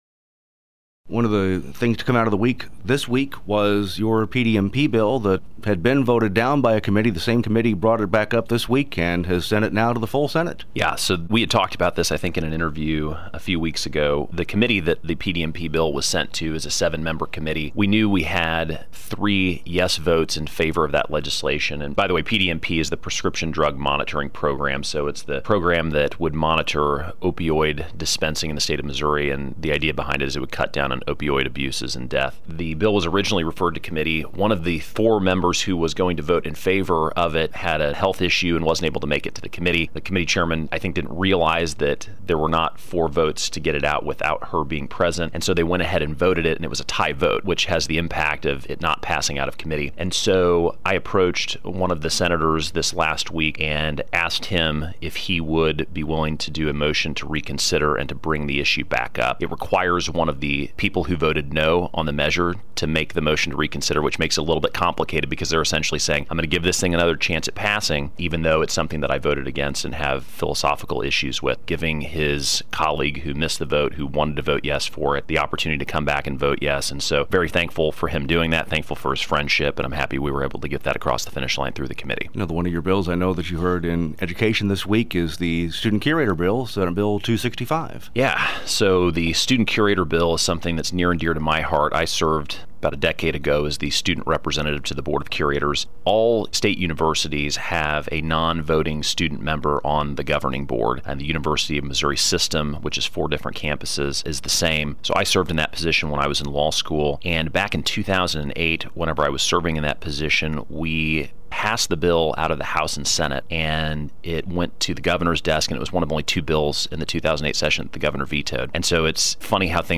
Audio: Sen. Luetkemeyer Discusses Priority Legislaton